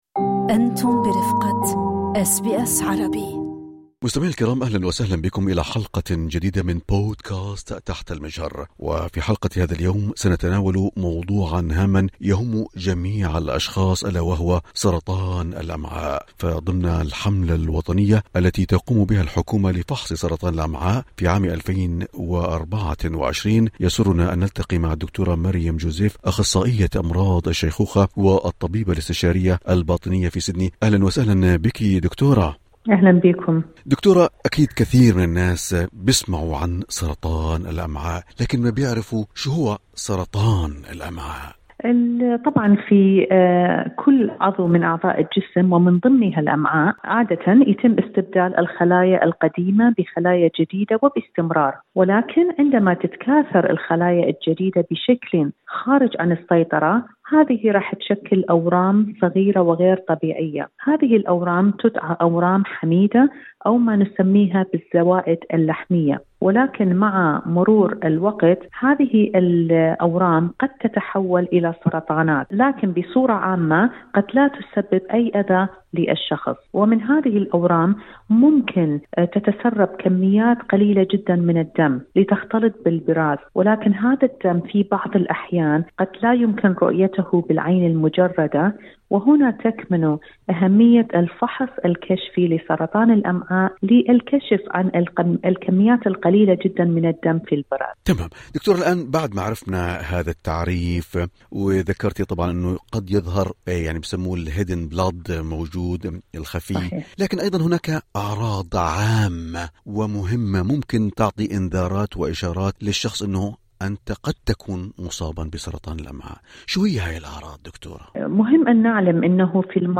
تنويه: المعلومات المقدمة في هذا اللقاء ذات طبيعة عامة، ولا يمكن اعتبارها نصيحة مهنية، نظراً لأن الظروف الفردية قد تختلف من شخص لآخر، ويجب عليكم استشارة خبير مستقل إذا لزم الأمر.